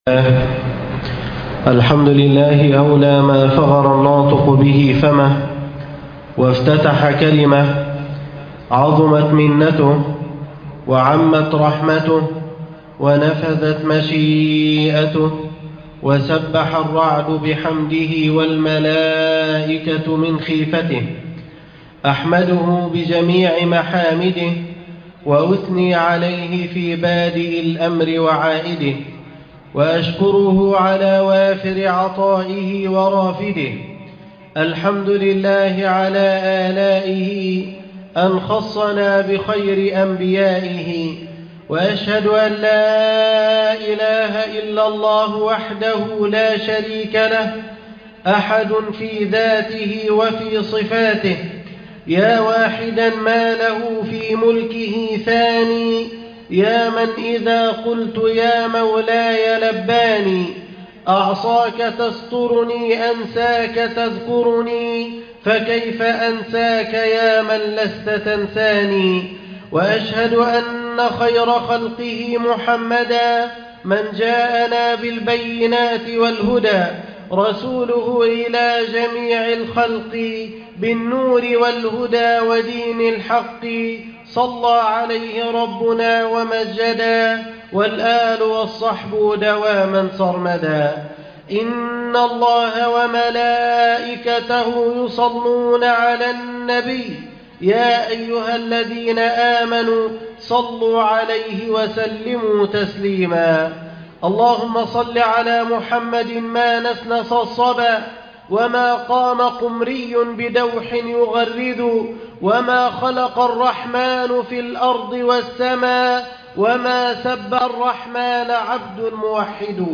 في ظلال السيرة النبوية الخطبة التاسعة